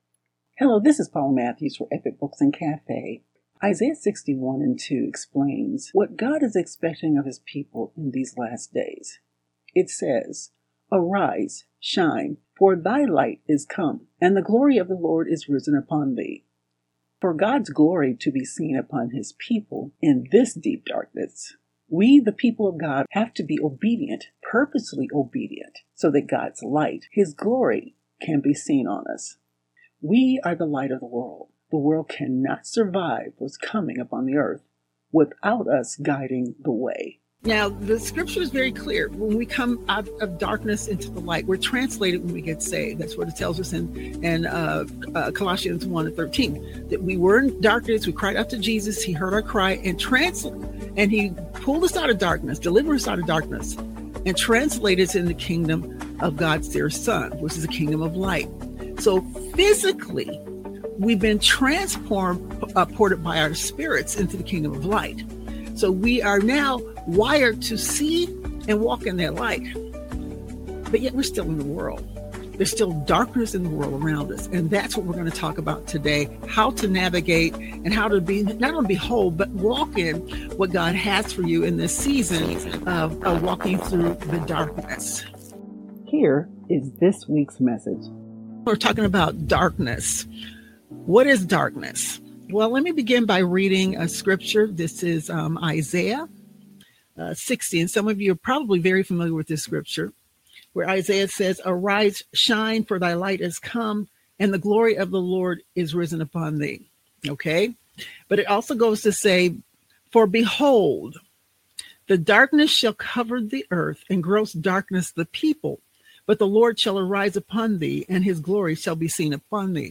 (We apologize for the periodic audio distortion.)